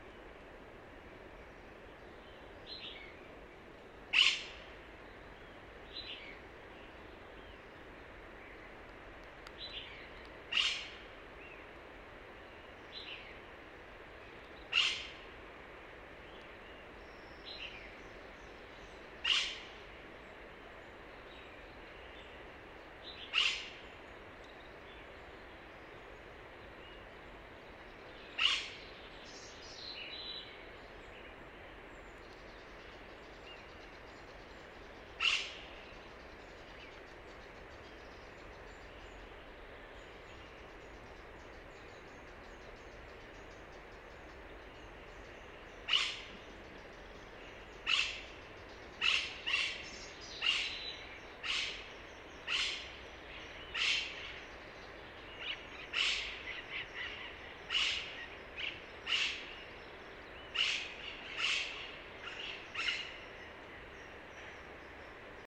Cachaña (Enicognathus ferrugineus)
Nombre en inglés: Austral Parakeet
Fase de la vida: Adulto
Localidad o área protegida: Parque Nacional Nahuel Huapi
Condición: Silvestre
Certeza: Observada, Vocalización Grabada